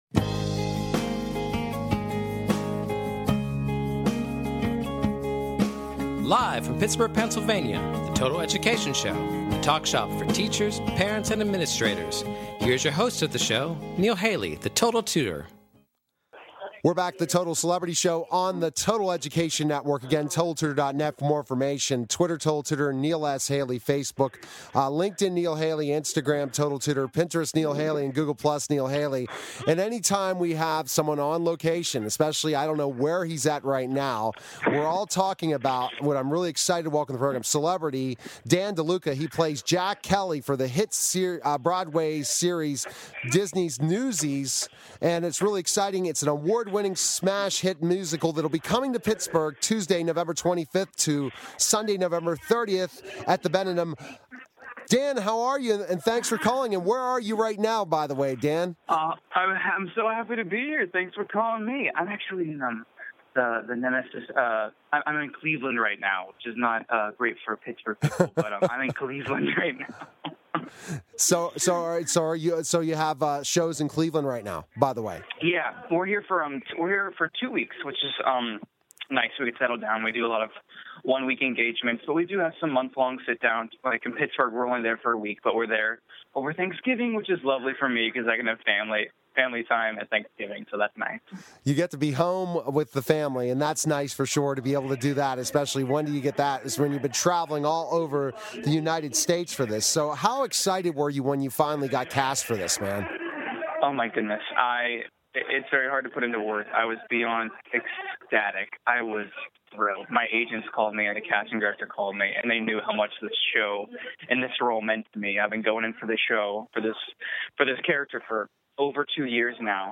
Catch weekly discussions focusing on current education news at a local and national scale. Check the weekly schedule to find out more about our featured celebrity guests and various other guests from the education community, including teachers, parents, principals, therapists, professors and service providers.